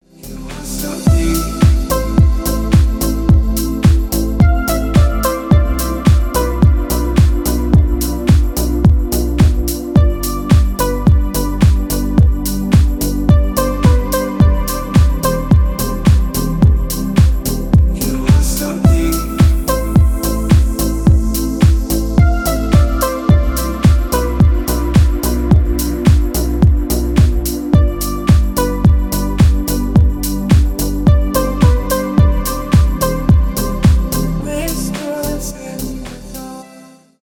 романтические , красивые
deep house